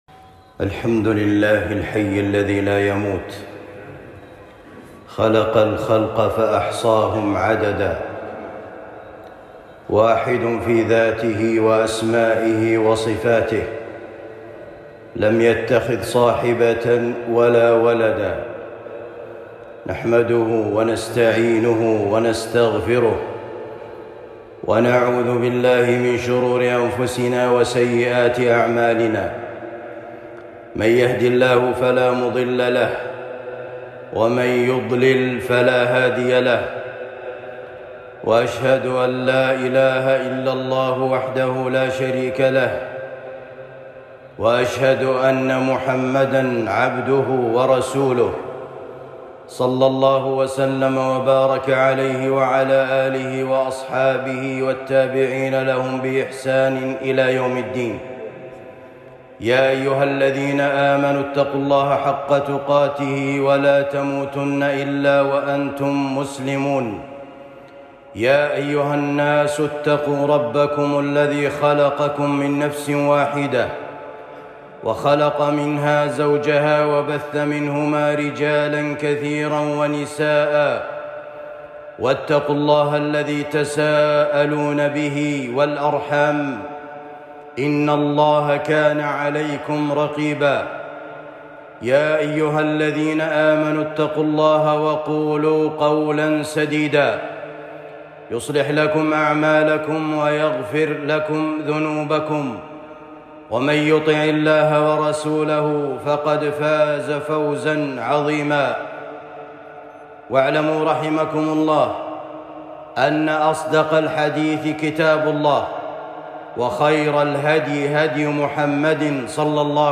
لا تشاركوا الكفار في أعيادهم خطبة جمعة